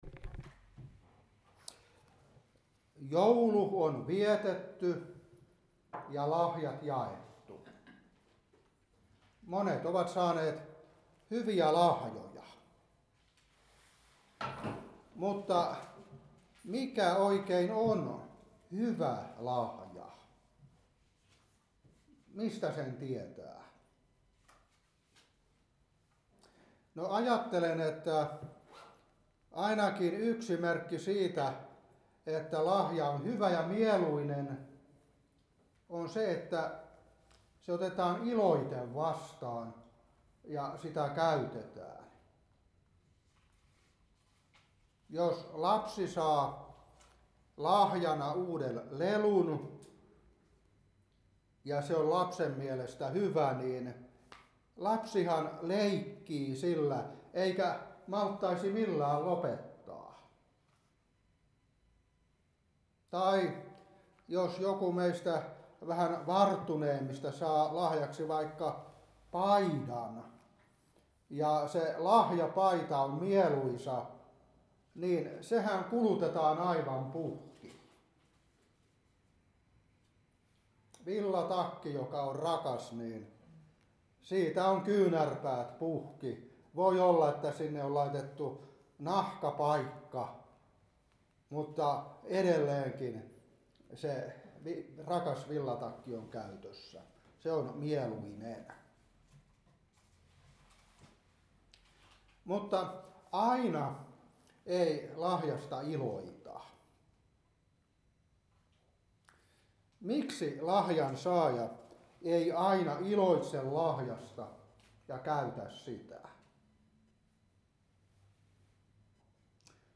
Seurapuhe 2026-1.